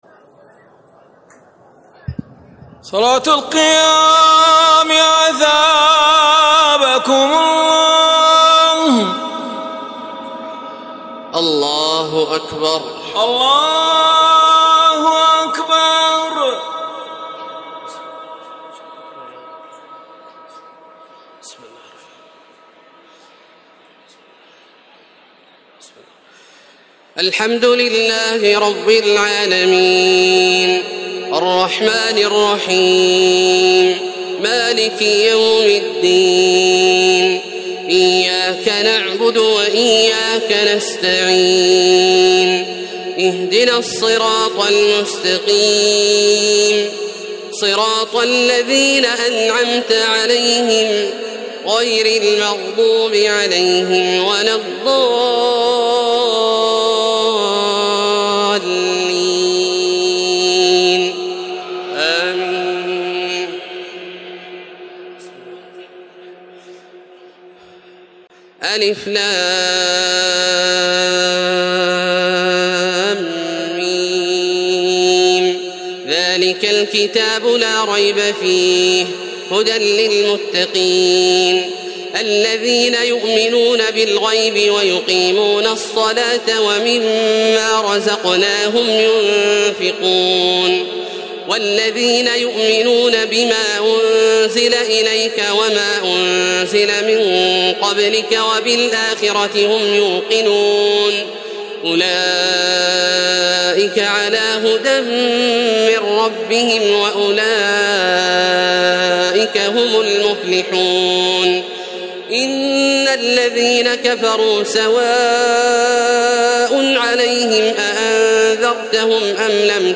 تراويح الليلة الأولى رمضان 1432هـ من سورة البقرة (1-86) Taraweeh 1st night Ramadan 1432 H from Surah Al-Baqara > تراويح الحرم المكي عام 1432 🕋 > التراويح - تلاوات الحرمين